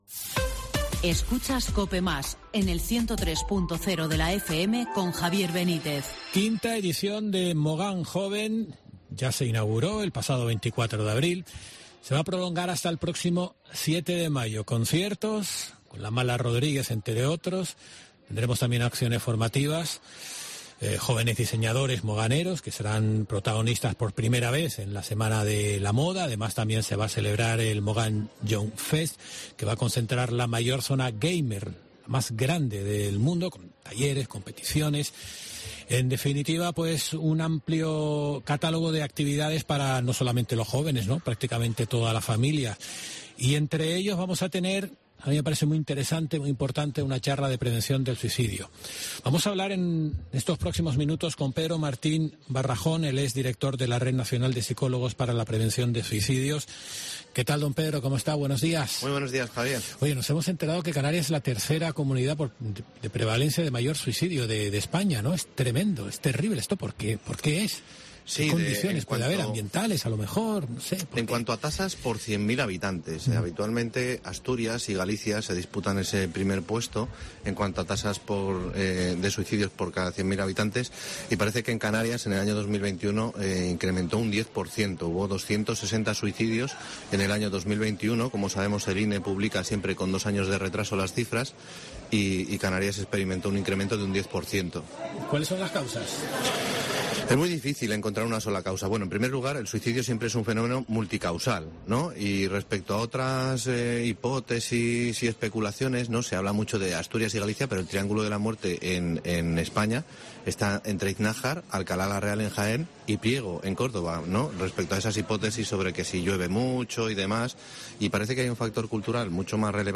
La Mañana de COPE Gran Canaria se desplazó en la mañana de hoy al municipio de Mogán para dar a conocer los detalles de la quinta edición del evento Mogán Joven que se celebrará hasa el próximo 7 de mayo, programa de actividades formativas y lúdicas gratuitas con el objetivo de situar a los jóvenes en primer plano de las políticas municipales